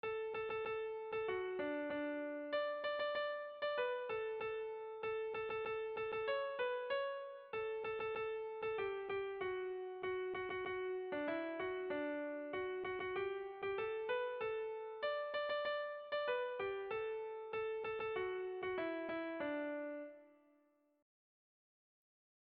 Haurrentzakoa
ABDE